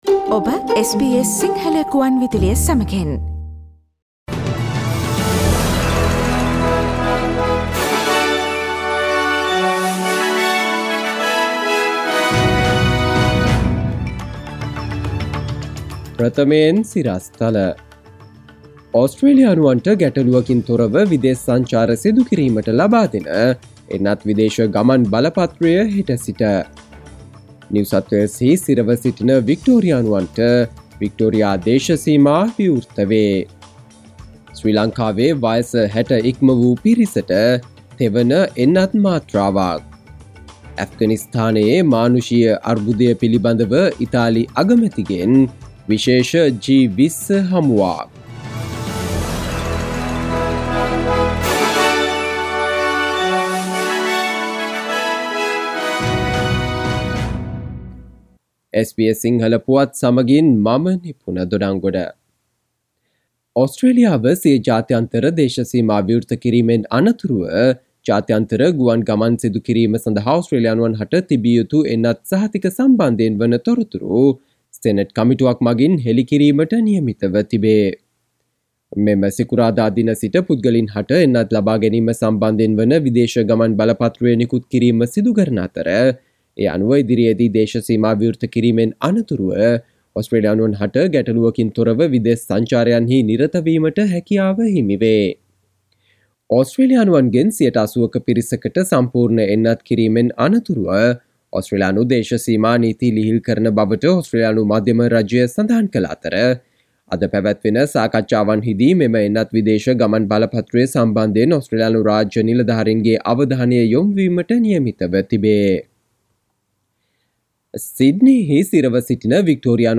සවන්දෙන්න 2021 සැප්තැම්බර් 30 වන බ්‍රහස්පතින්දා SBS සිංහල ගුවන්විදුලියේ ප්‍රවෘත්ති ප්‍රකාශයට...